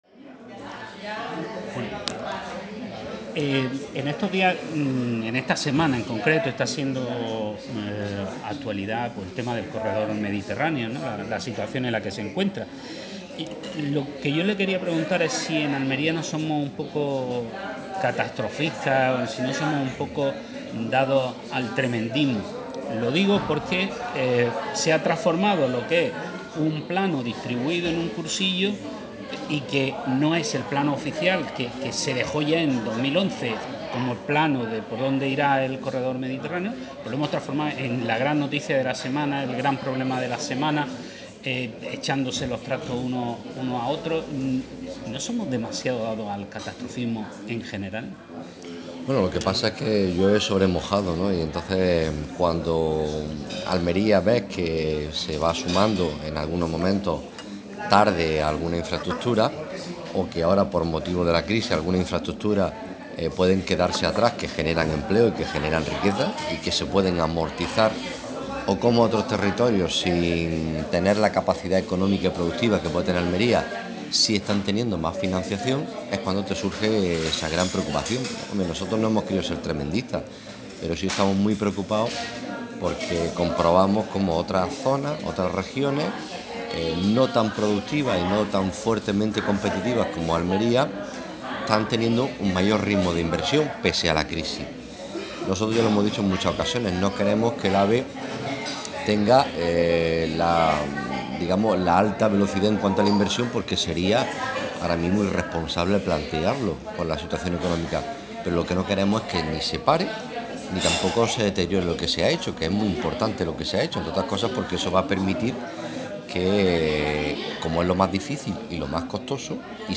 jcpnentrevista.mp3